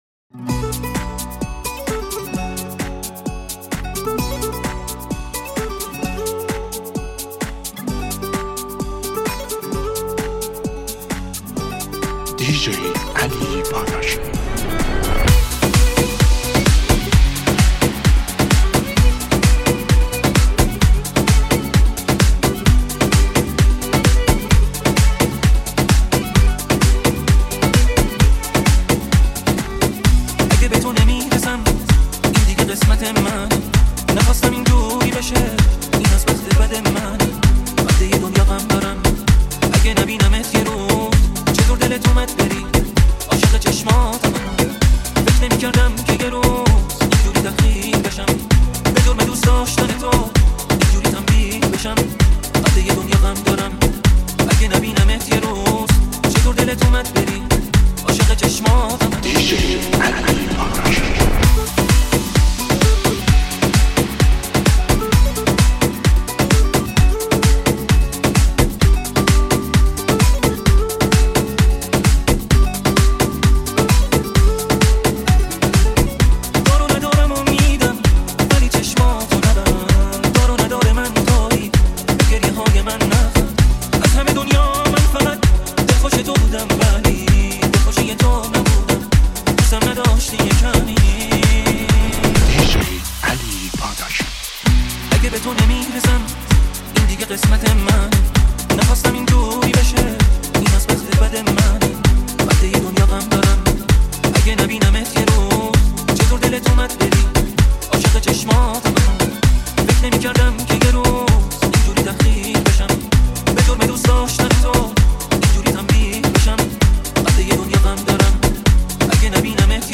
+ ریمیکس با بیس بالا اضافه شد